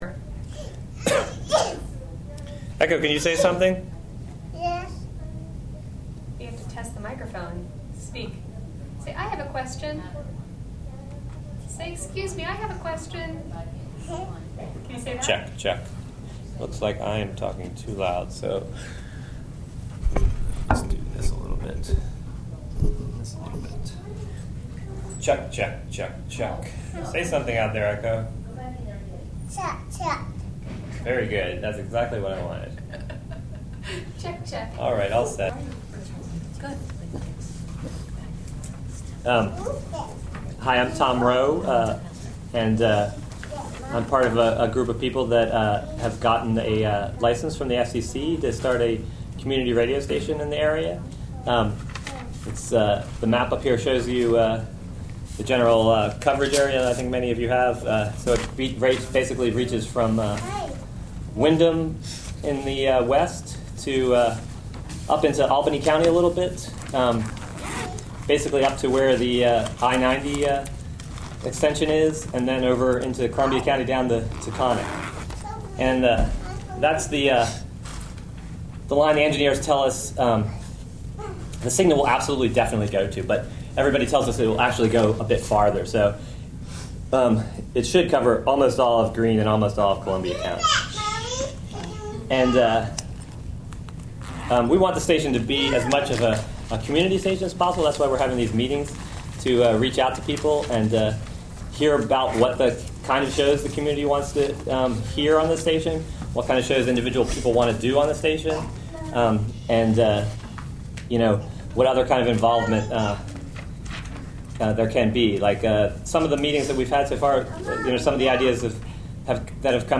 WGXC Public Meeting_Cairo Town Hall/Library_030709.mp3 (Audio)